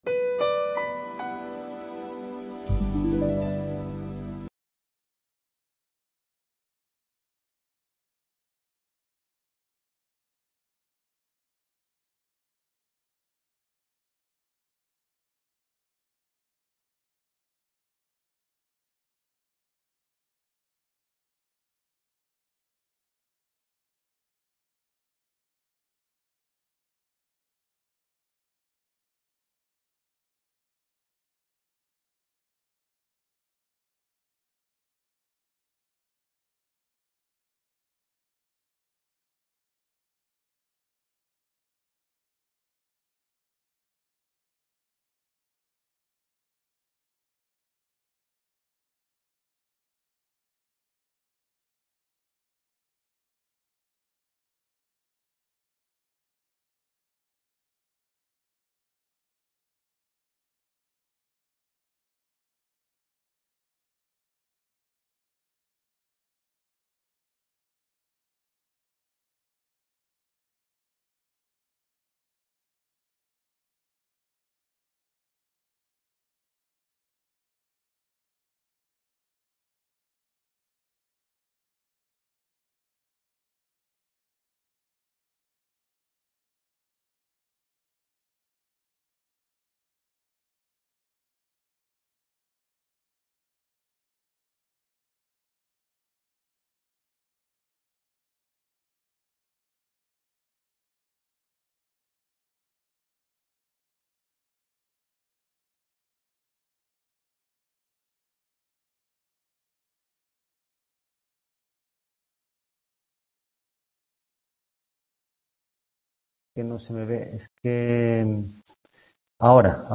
En la grabación se exponen los capítulos en cuestión aclarando dudas de los estudiantes al hilo de la exposición.